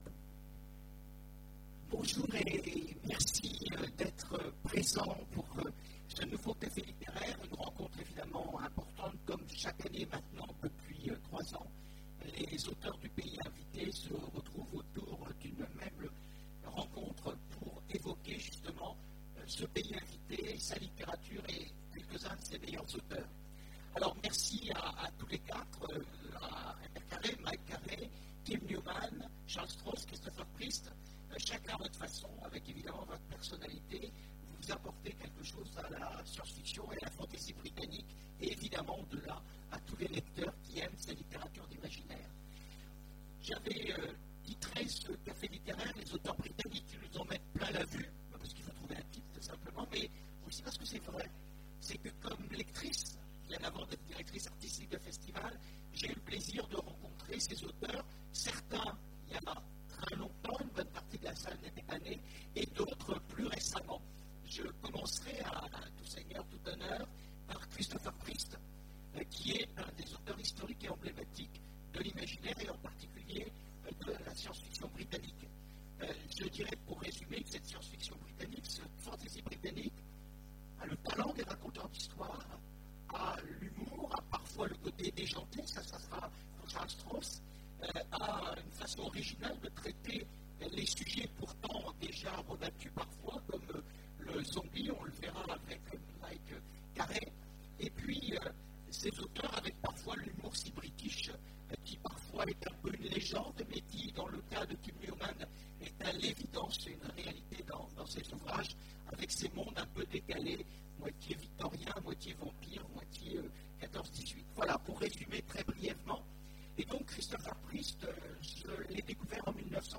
Imaginales 2015 : Conférence Les auteurs britanniques...